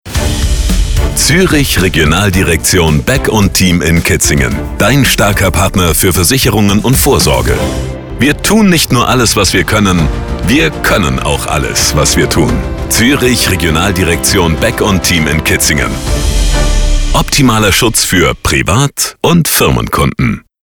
radiospot-rd-beck.mp3